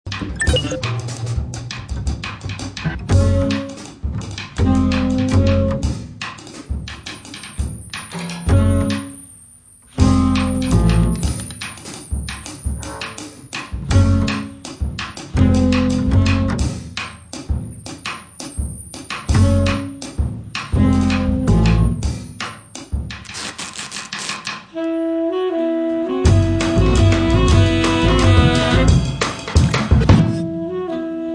One of 100 jazz albums that shook the world (Jazzwise, 2006)